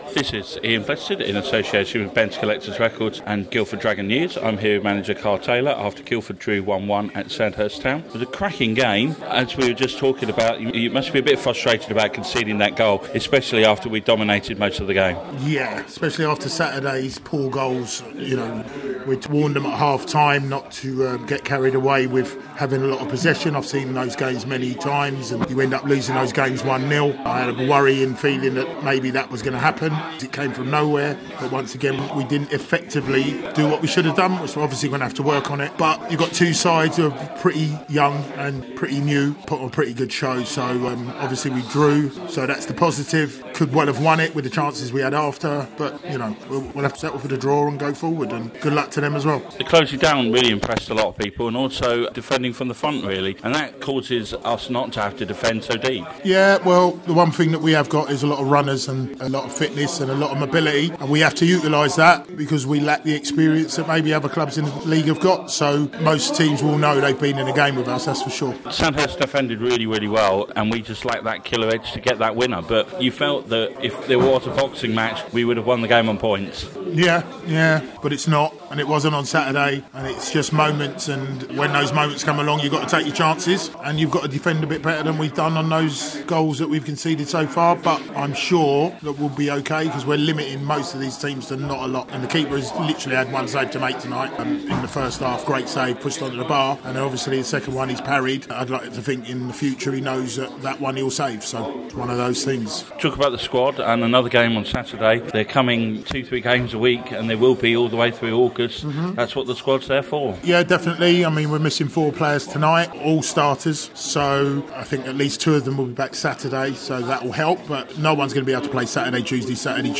post-match interview